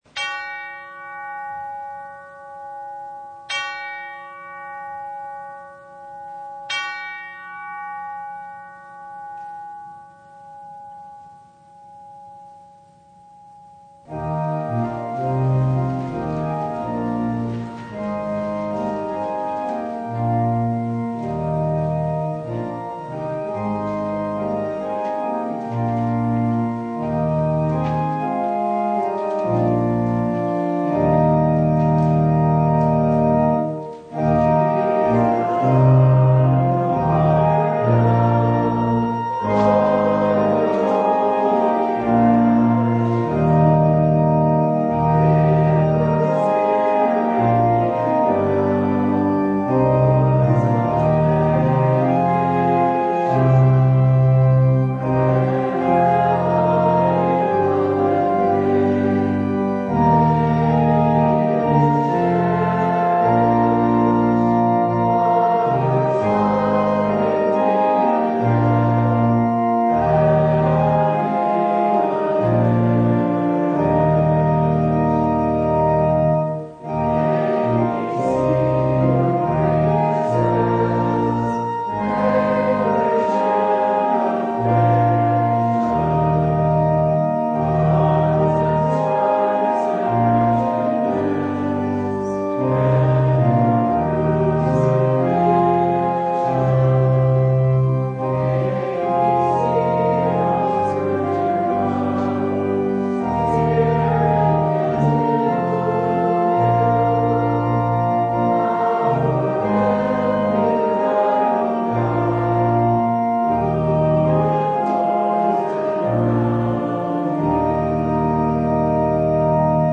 March 21, 2021 The Fifth Sunday in Lent (audio recording) Passage: Jeremiah 31:31-34 Service Type: Sunday Behold, the days are coming, declares the Lord, when I will make a new covenant.